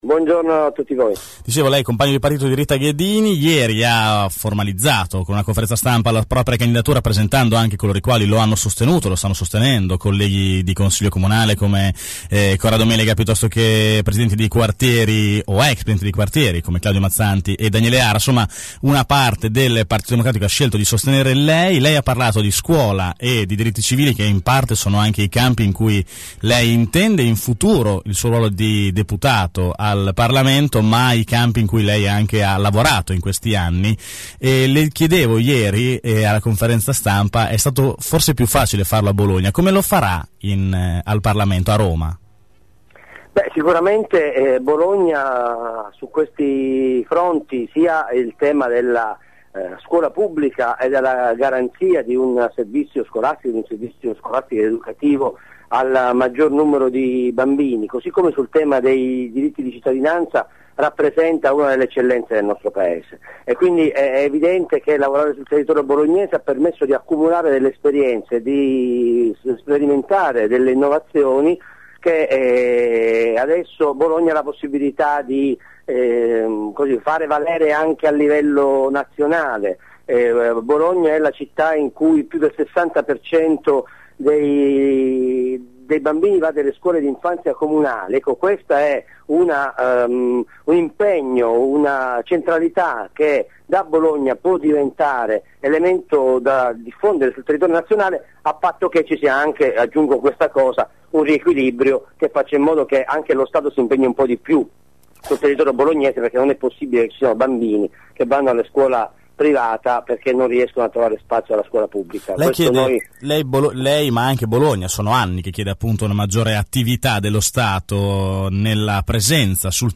Venerdì mattina durante AngoloB abbiamo organizzato una tavola rotonda con alcuni dei candidati.
Per il Pd abbiamo intervistato:
Sergio Lo Giudice, capogruppo in Comune a Bologna